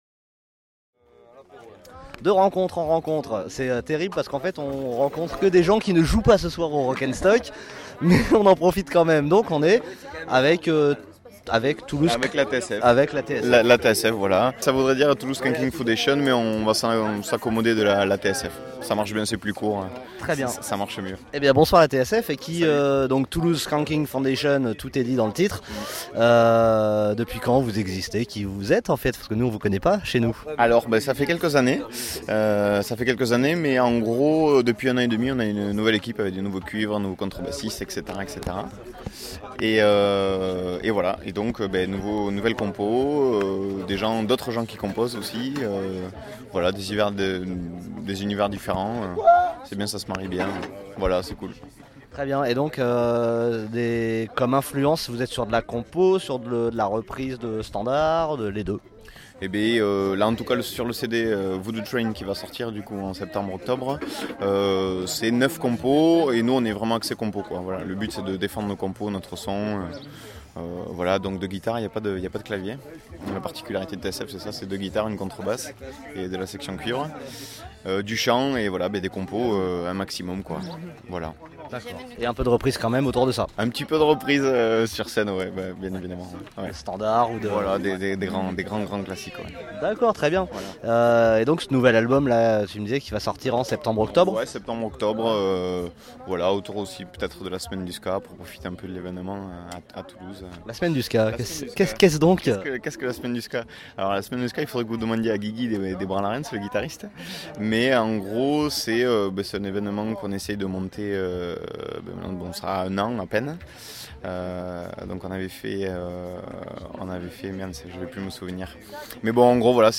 Interviews
Festival Rock'n'Stock | Preignan | 5-7 juillet 2013